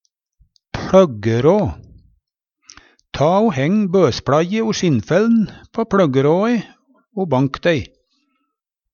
pLøggerå - Numedalsmål (en-US)